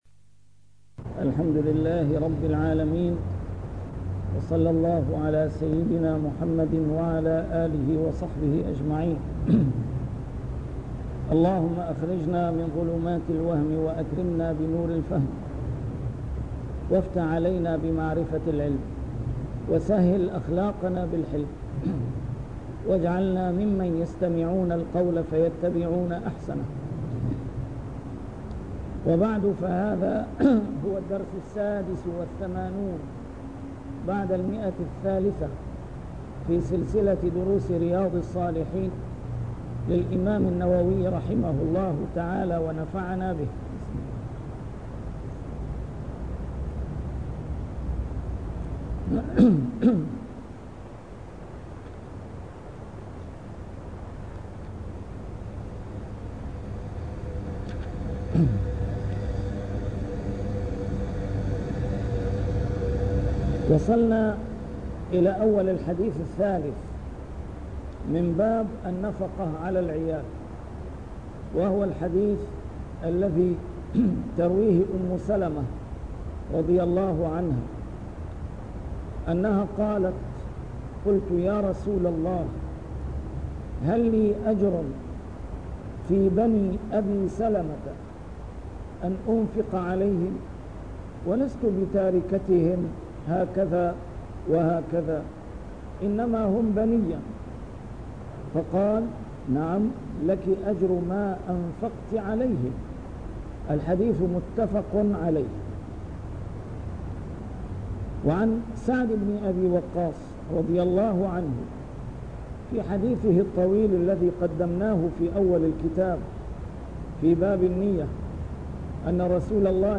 A MARTYR SCHOLAR: IMAM MUHAMMAD SAEED RAMADAN AL-BOUTI - الدروس العلمية - شرح كتاب رياض الصالحين - 386- شرح رياض الصالحين: النفقة على العيال